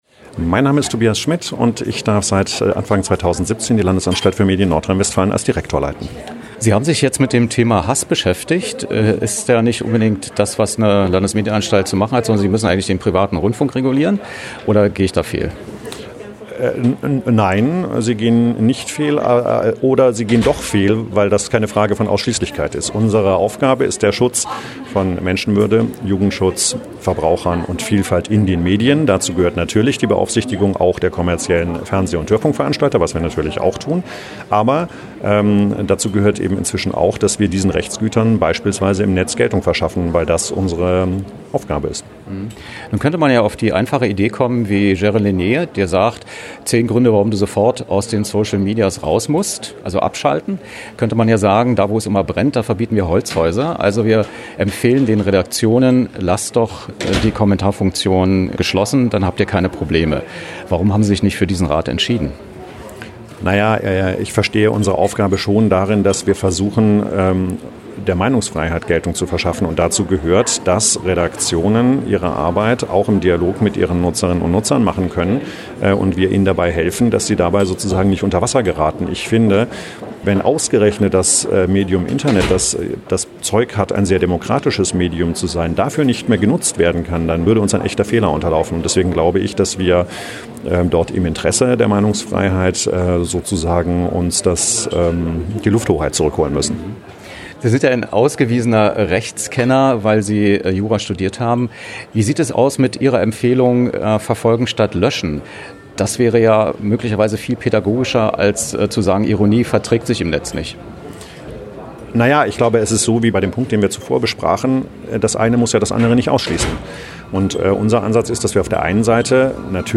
Wer: Dr. Tobias Schmid, Direktor der Landesanstalt für Medien Nordrhein-Westfalen
Was: Interview nach der Präsentation „Hasskommentare im Netz – Steuerungsstrategien für Redaktionen“
Wo: Haus der Bundespressekonferenz, Schiffbauerdamm 40, 10117 Berlin